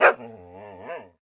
Divergent/bdog_panic_3.ogg at main
bdog_panic_3.ogg